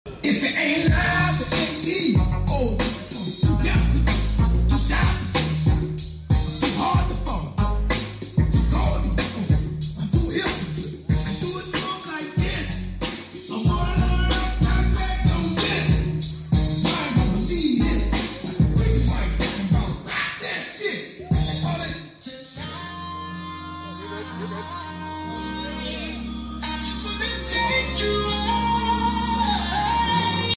bike night at homestead park sound effects free download